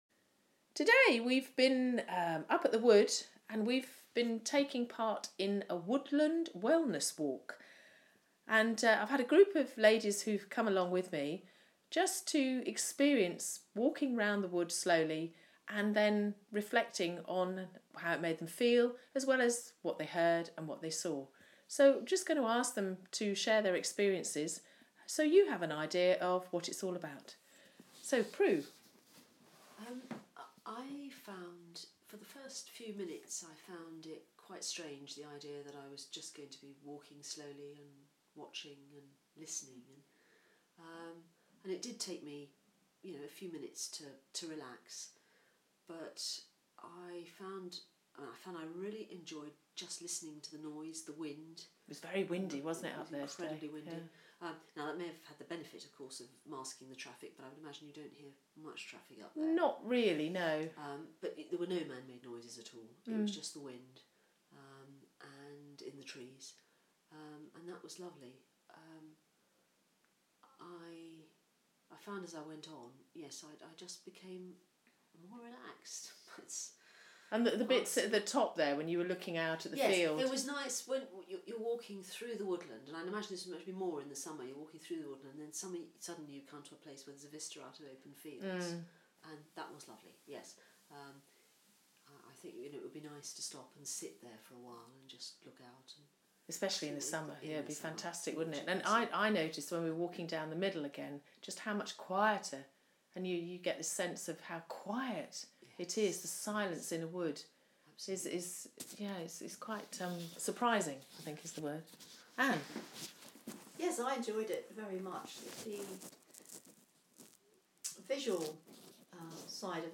Feedback from a group who spent a few hours experiencing a wild woodland environment